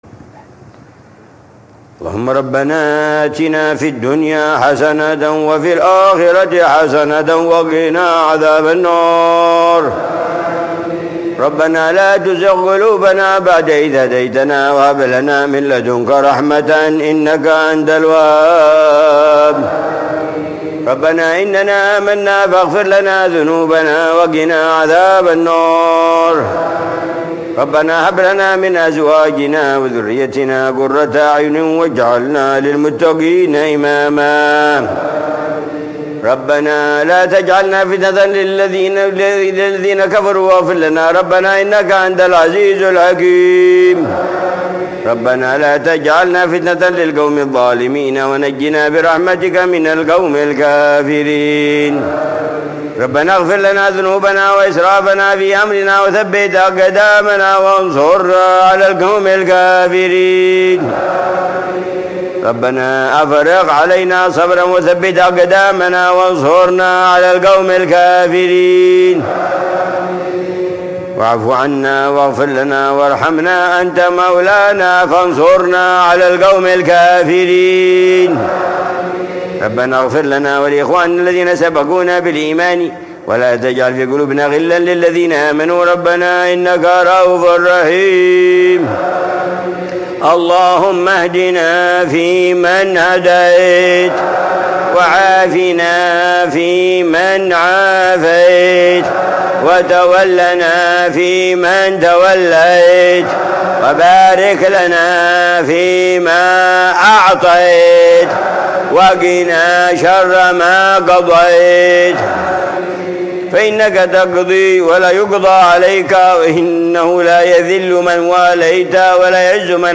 دعاء العلامة الحبيب عمر بن حفيظ في قنوت الوتر، ليلة الخميس 20 رمضان 1446هـ ( إنما يوفى الصابرون أجرهم بغير حساب )